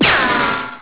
pistol.wav